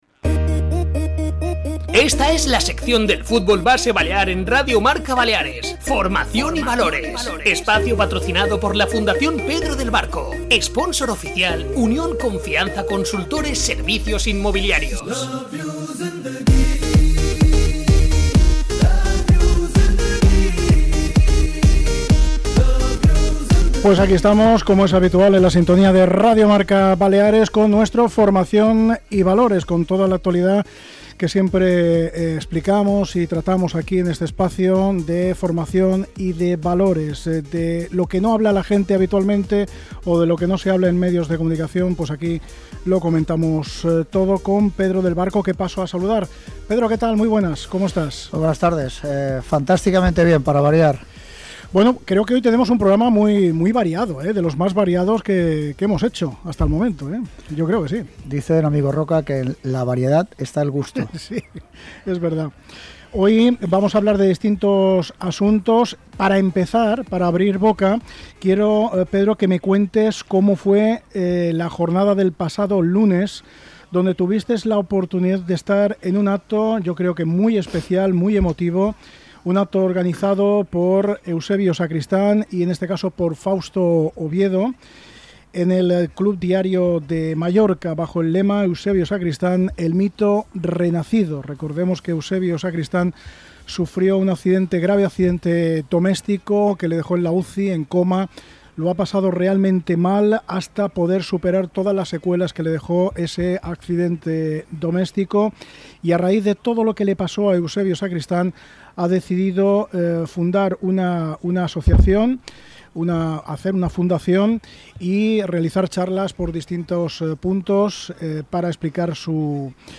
'Formación y Valores' entrevista a deportistas destacados de las Islas Baleares - Fibwi Diario